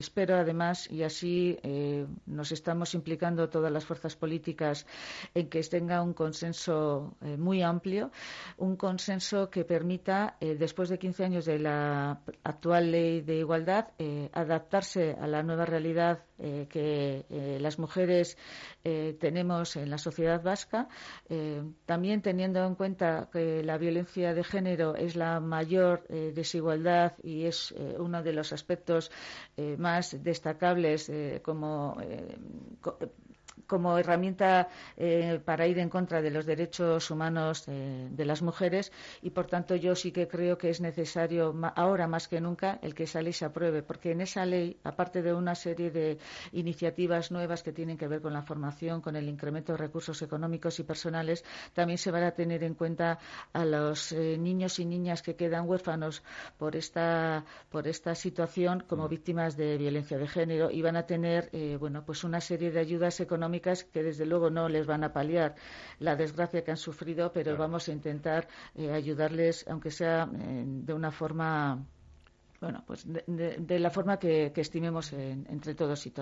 Después de que ayer fuera desestimada la enmienda a la totalidad, la consejera de igualdad, justicia y políticas sociales del Gobierno Vasco ha señalado en nuestros micrófonos que los partidos políticos "se están implicando en busca de un consenso amplio" que permita actualizar una ley que ya tiene 15 años.